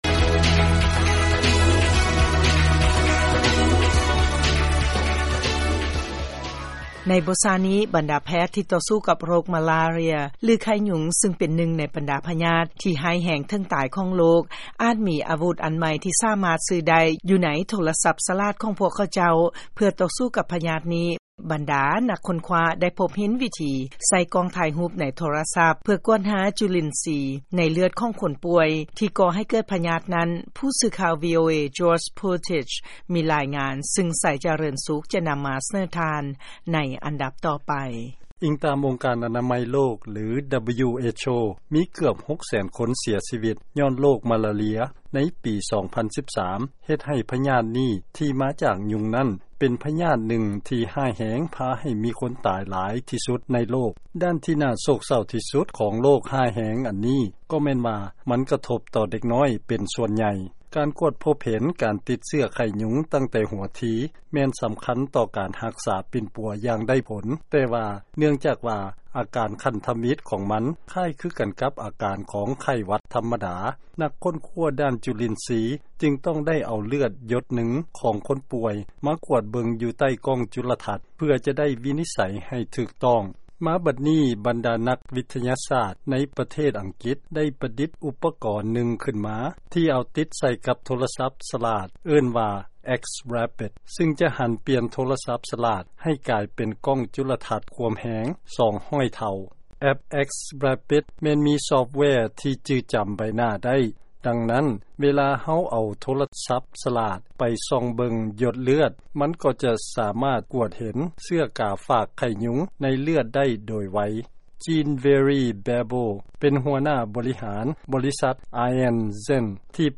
ຟັງລາຍງານ ໂທລະສັບສະຫລາດ ໃຊ້ເປັນເຄື່ອງມື ວິນິດໄສ ໂຣກມາລາເຣຍ ໄດ້.